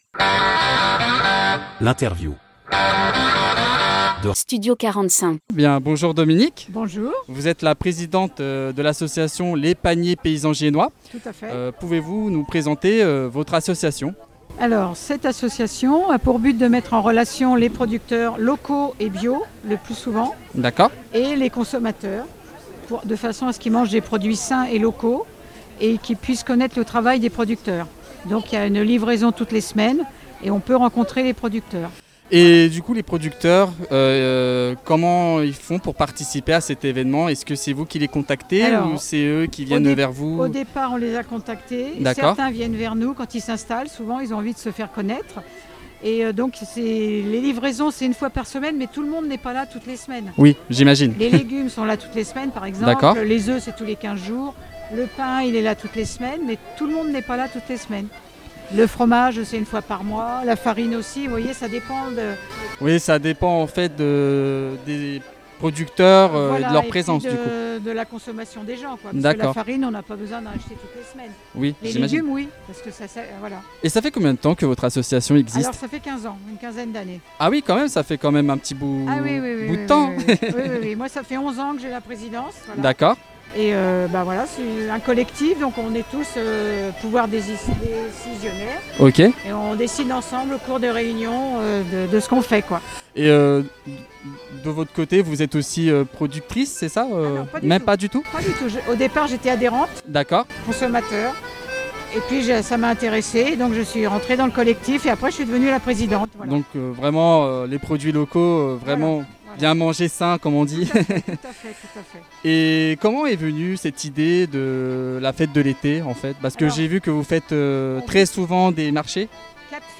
Elles nous parlent de leurs actions pour promouvoir une agriculture locale, responsable et solidaire, à travers les paniers de produits frais, les circuits courts et l’insertion par le travail. Un échange chaleureux et authentique, enregistré en plein cœur de l’événement, qui témoigne de l’énergie collective au service d’une alimentation durable.